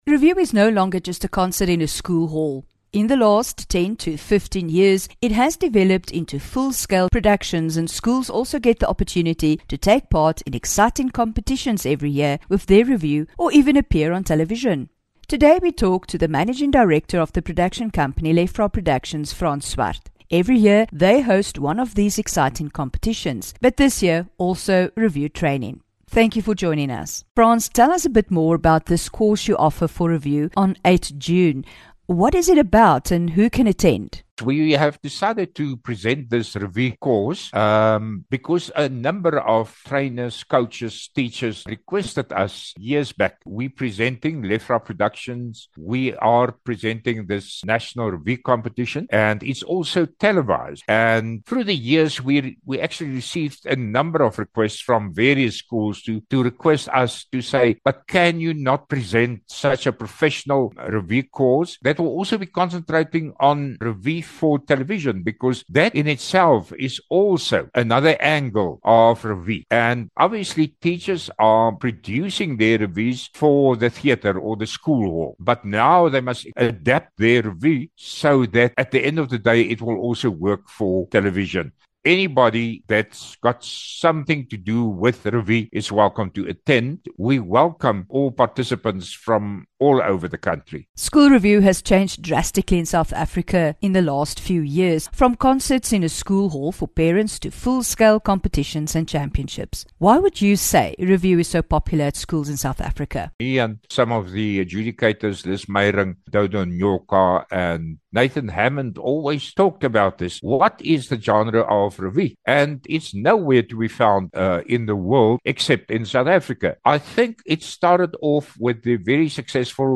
11 Jun INTERVIEW